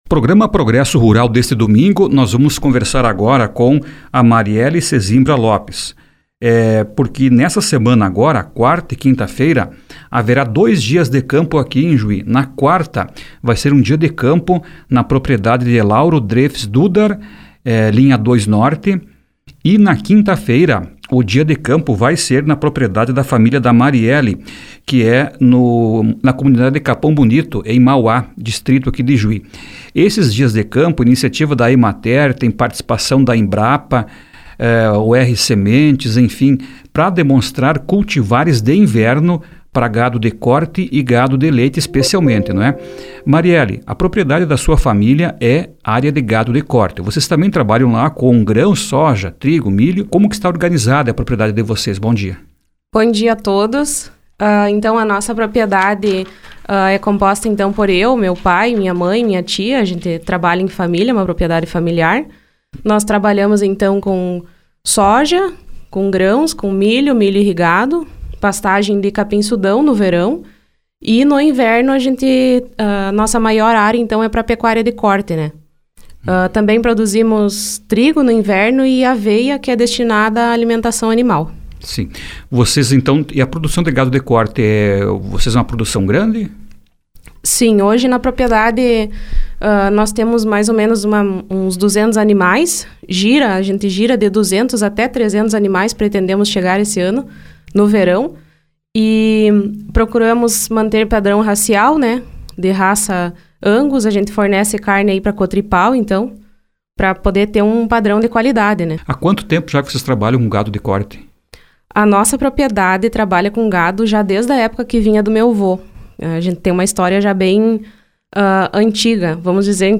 entrevista completa